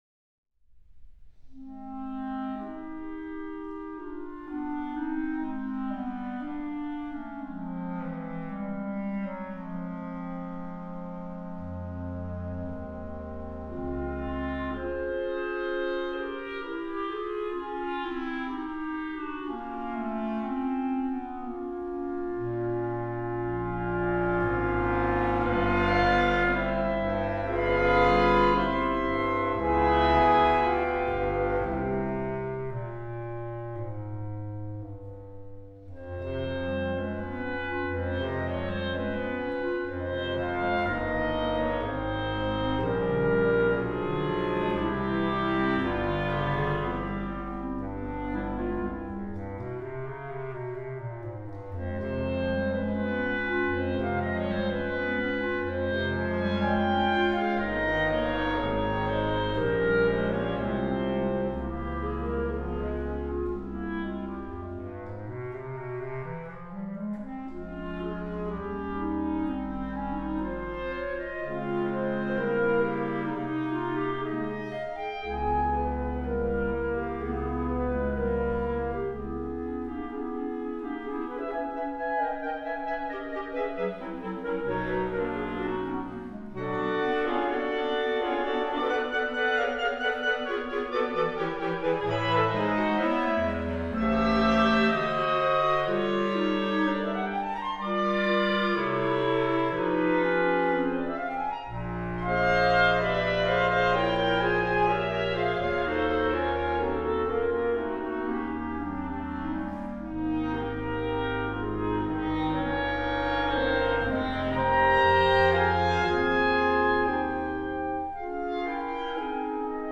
für Klarinettenchor